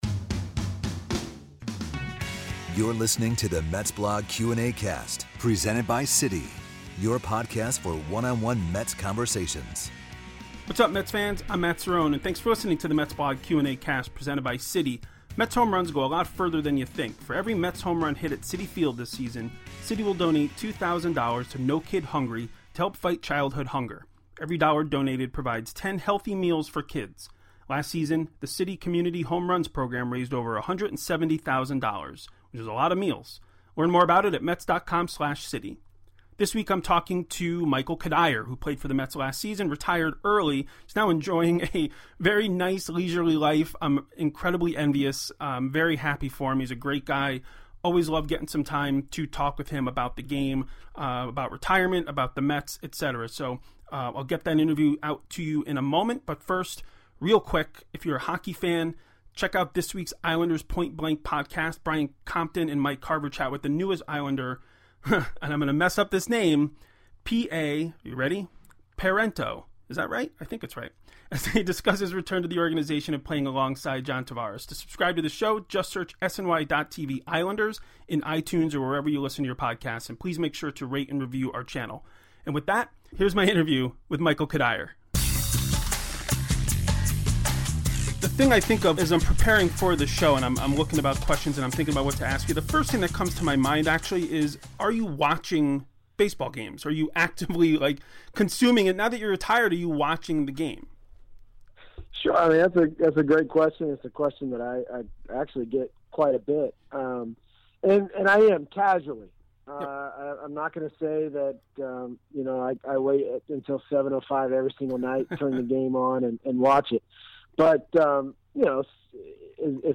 MetsBlog Q&Acast: Michael Cuddyer interview